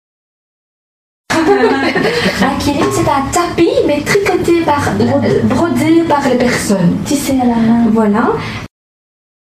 uitspraak voorbeeld